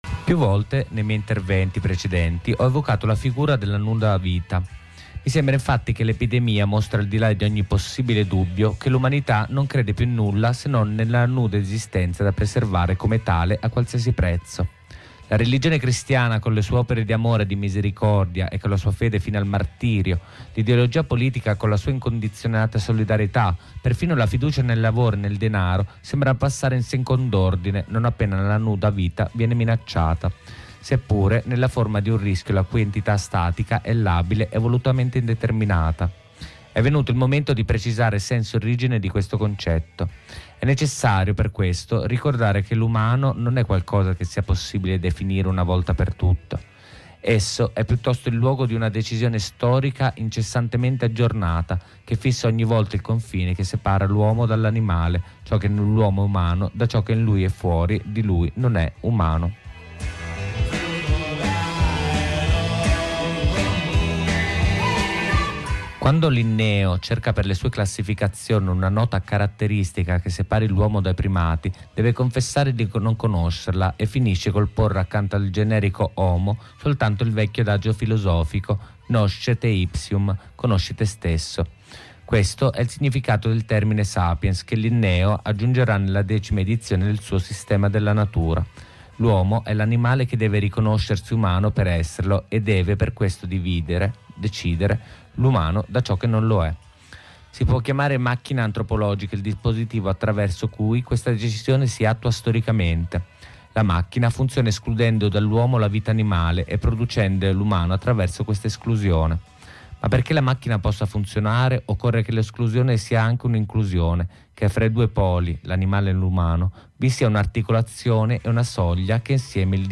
Lettura da Giorgio Agamben “La nuda vita ed il vaccino ” :